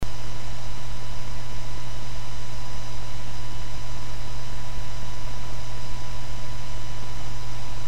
The test was done outside the casing with unidirectional microphone pointing directly to the fan.